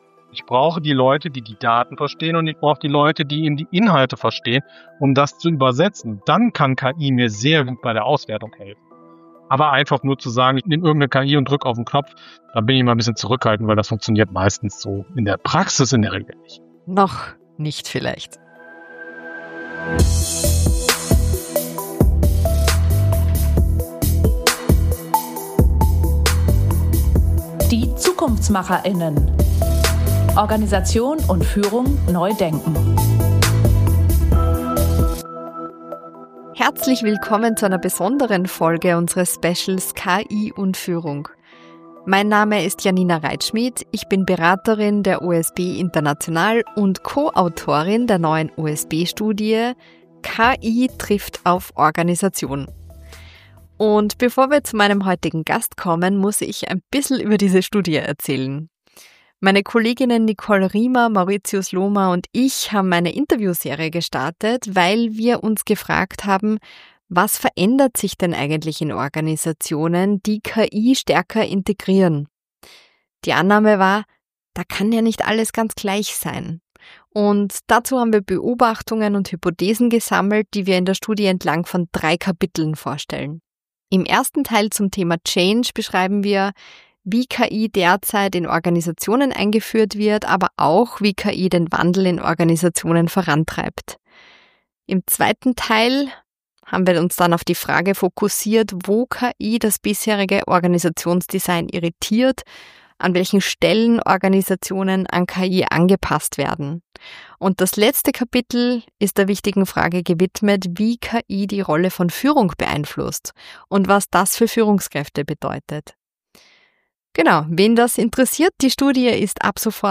Im Gespräch geht es vor allem um folgende Themen: wie KI Entscheidungsprozesse verändert, warum Führungskräfte ein noch klareres „Warum“ brauchen, weshalb End-to-End-Denken für Organisationen unverzichtbar wird und wie KI die Zeitlogiken in Unternehmen verschiebt.